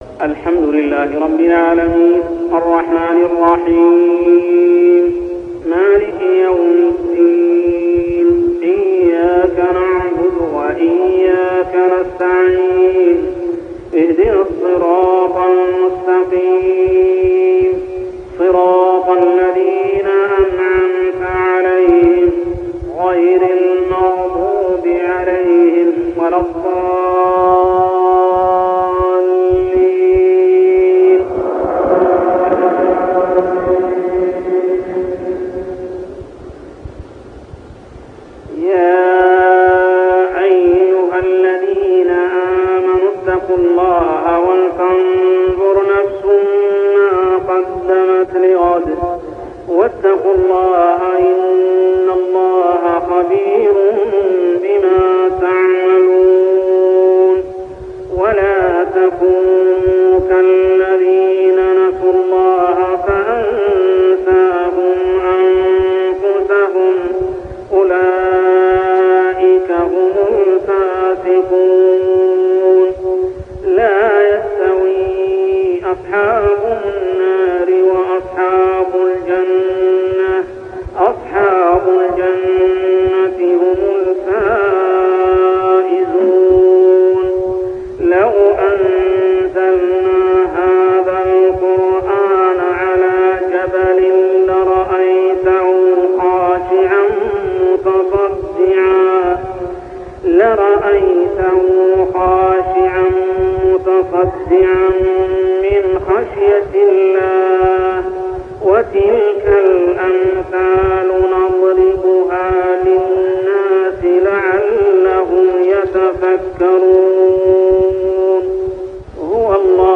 تلاوة من صلاة الفجر لخواتيم سورة الحشر 18-24 عام 1399هـ | Fajr prayer Surah Al-hashr > 1399 🕋 > الفروض - تلاوات الحرمين